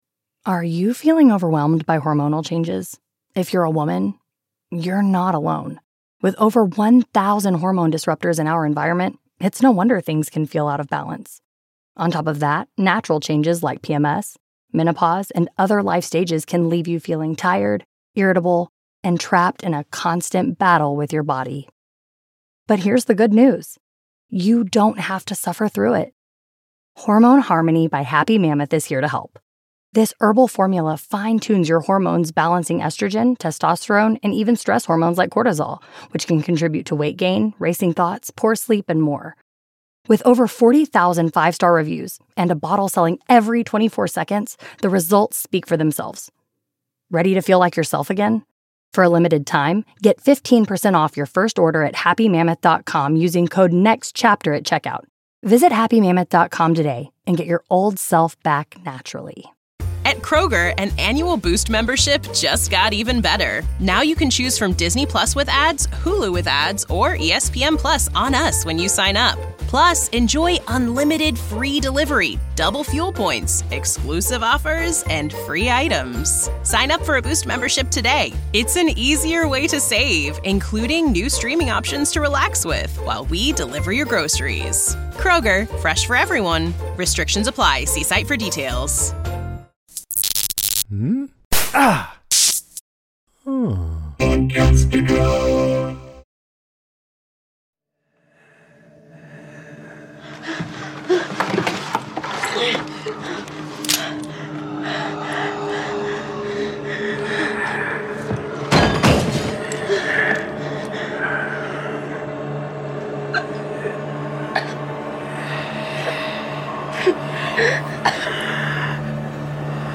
We have in-depth, fun conversations about TV shows like Cobra Kai, Yellowjackets, House of the Dragon, The Rings of Power, The Handmaid’s Tale, Andor, Dead to Me, and many more.